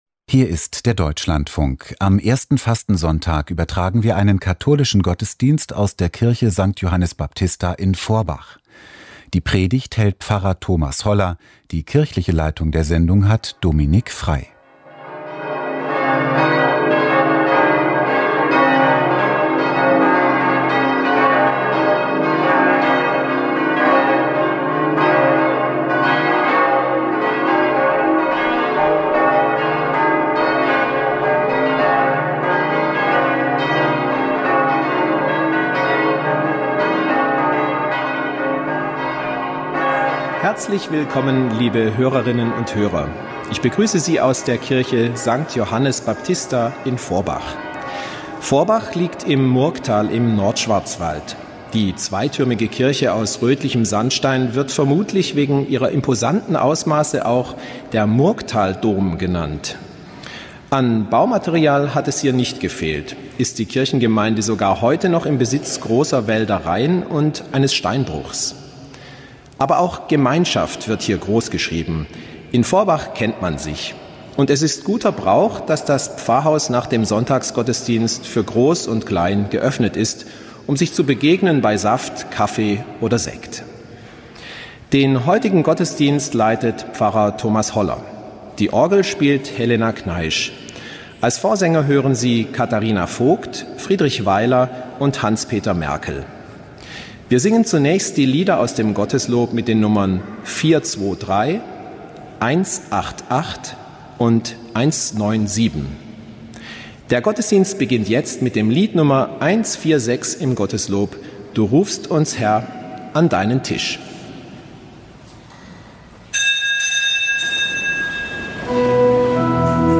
Katholischer Gottesdienst aus Forbach